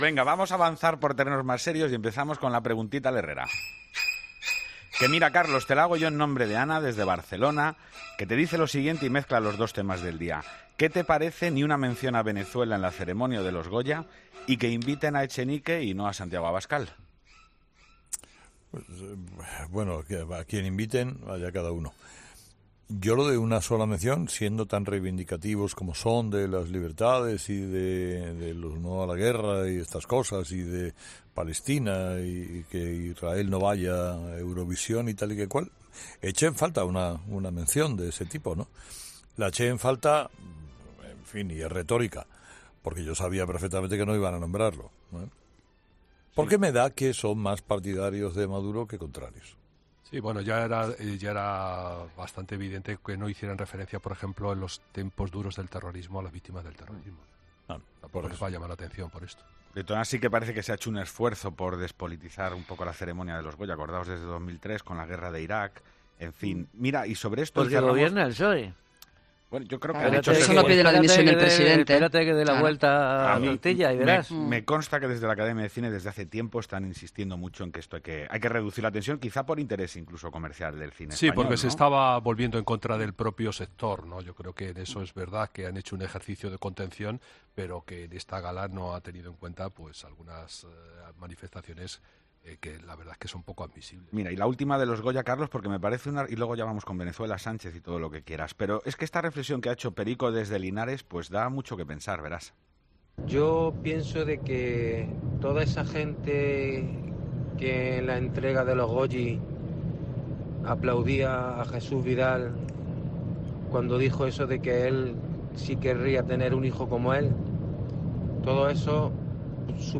La reflexión de un oyente de COPE sobre Jesús Vidal que remueve las conciencias de los actores "superprogres"